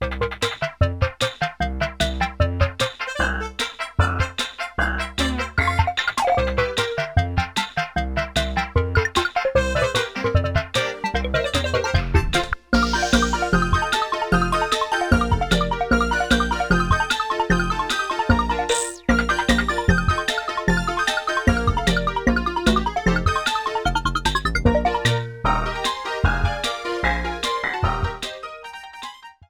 applied fade-out to last two seconds
Fair use music sample